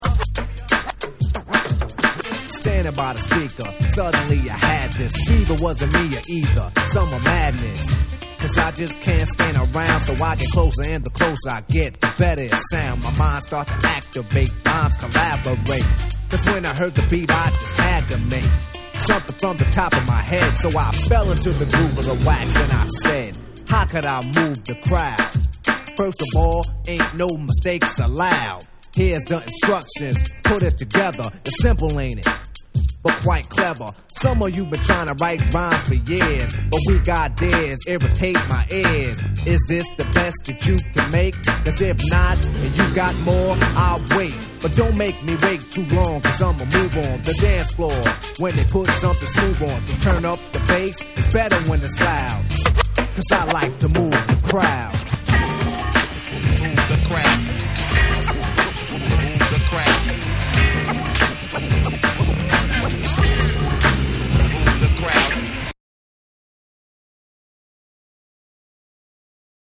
BREAKBEATS / HIP HOP